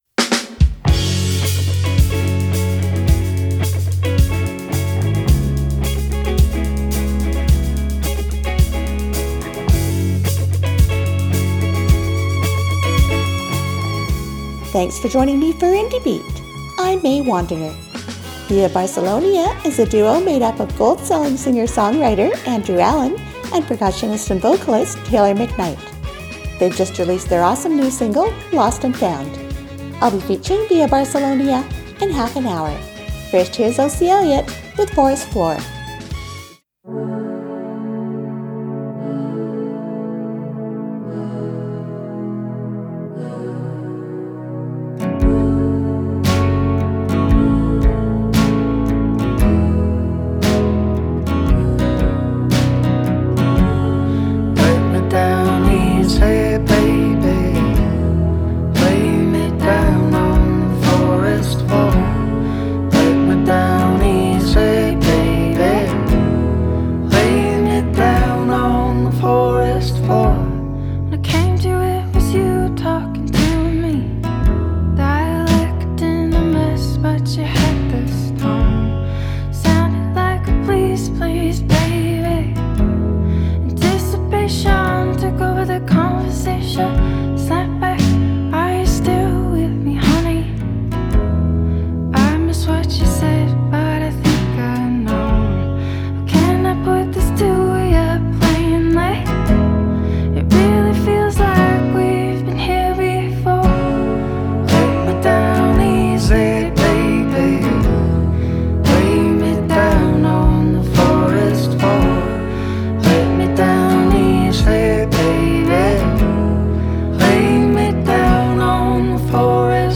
29 min feature of Via Barcelonia plus 27 min of Western Canadian indie music mix